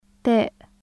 日本語の「タ」と同じ/t/の音です。ただし，/ti/は「チ」ではなく「ティ」，/tu/は「ツ」ではなく「トゥ」と発音するので気をつけましょう。 /t/ 無声・歯茎・破裂音/t/ ت /taːʔ/ t （アルファベット） أنت /ʔanta/ 貴男 （あなた） متى /mataː/ いつ أنت /ʔanti/ 貴女 （あなた） تين /tiːn/ いちじく أنتم /ʔantum/ あなたがた زيتون /zaytuːn/ オリーブの実 متر /mitr/ メートル زيت /zayt/ 油